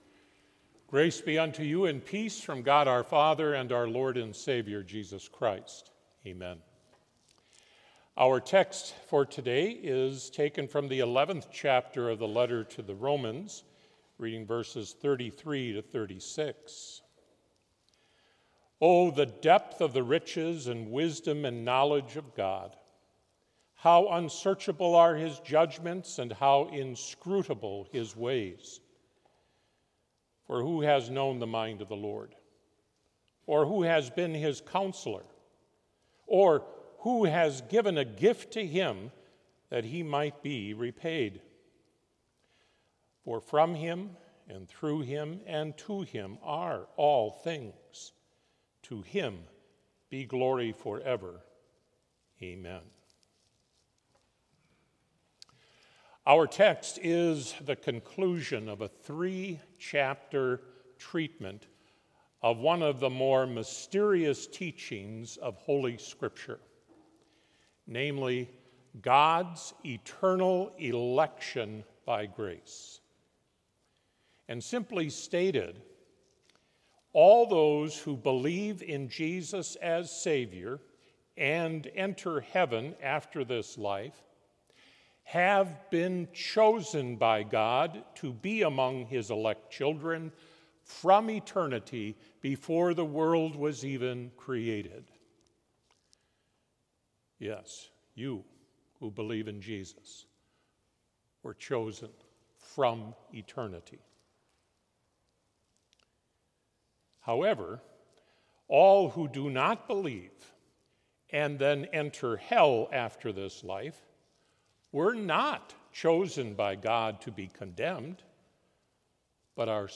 Chapel worship service held on September 5, 2024, BLC Trinity Chapel, Mankato, Minnesota
Complete service audio for Chapel - Thursday, September 5, 2024